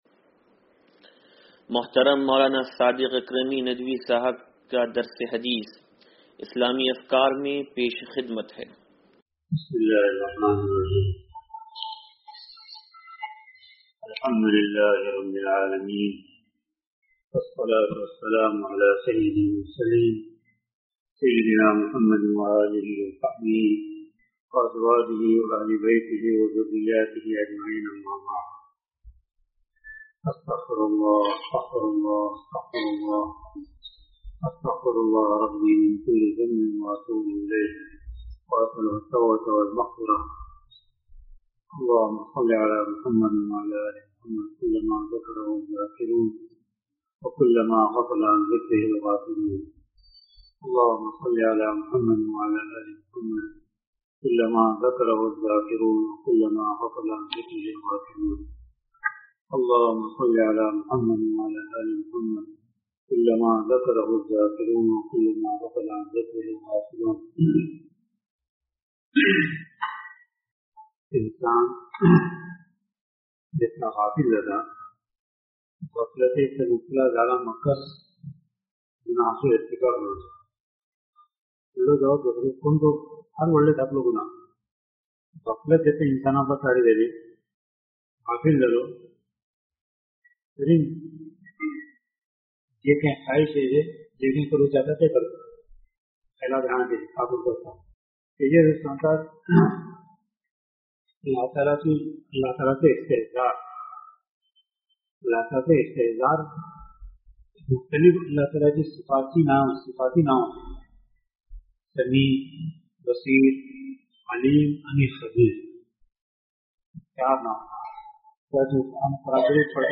درس حدیث نمبر 0457